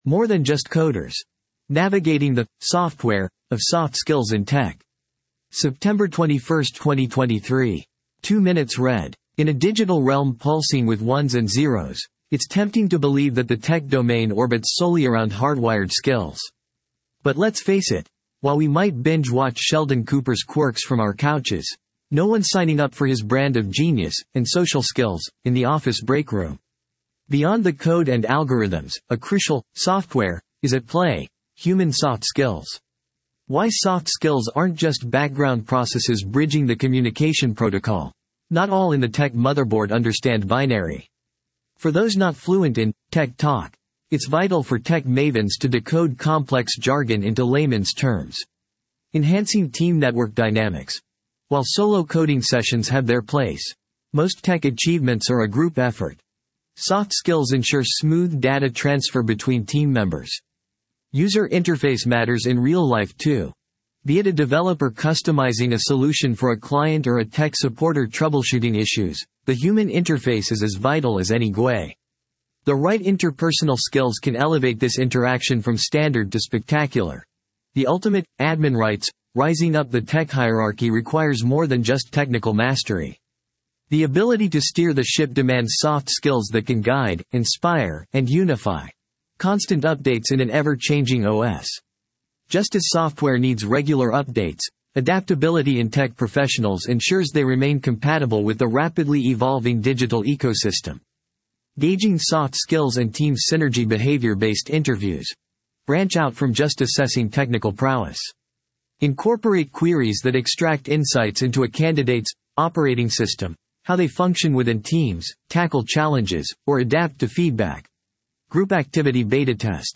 You can use this audio player to convert website page content into human-like speech. 11:11 00:00 / 14:00 1.0X 2.0X 1.75X 1.5X 1.25X 1.0X 0.75X 0.5X In a digital realm pulsing with 1s and 0s, it's tempting to believe that the tech domain orbits solely around hardwired skills.